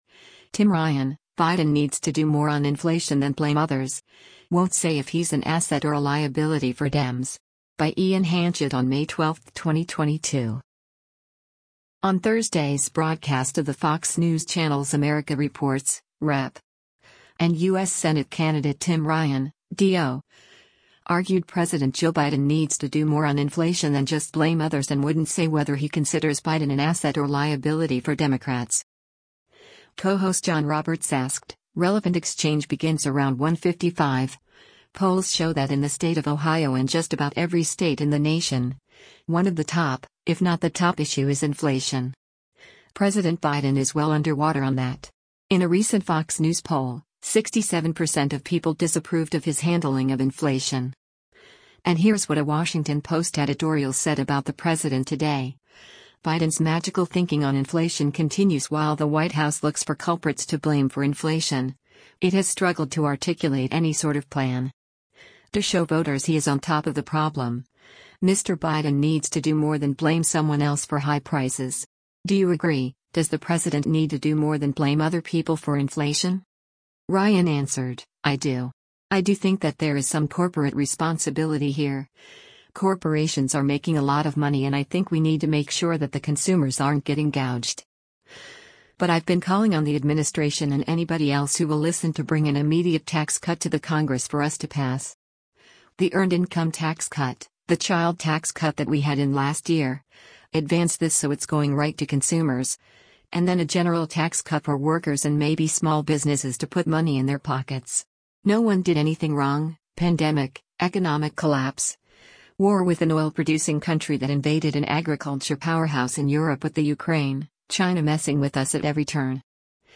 On Thursday’s broadcast of the Fox News Channel’s “America Reports,” Rep. and U.S. Senate candidate Tim Ryan (D-OH) argued President Joe Biden needs to do more on inflation than just blame others and wouldn’t say whether he considers Biden an asset or liability for Democrats.